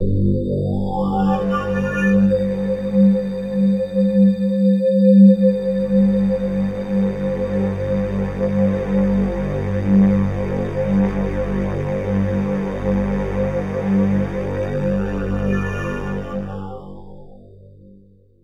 Index of /90_sSampleCDs/Club_Techno/Sweeps
Sweep_3_G2.wav